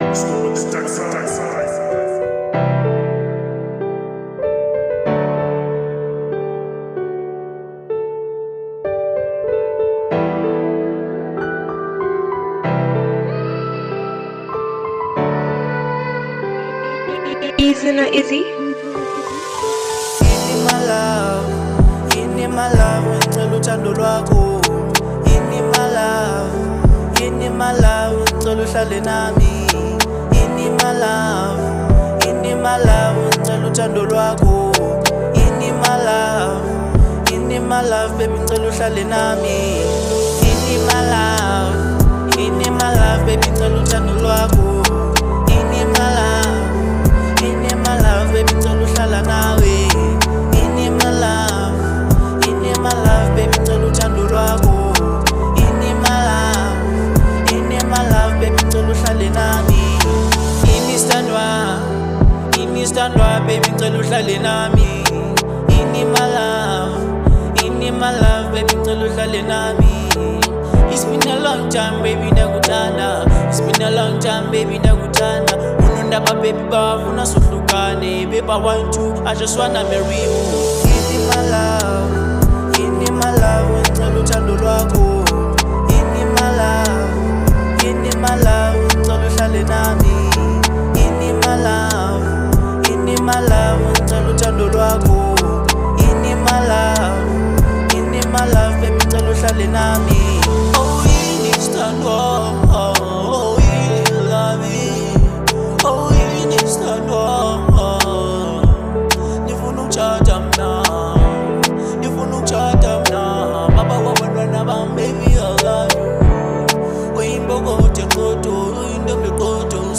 02:50 Genre : RnB Size